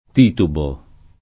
titubare v.; titubo [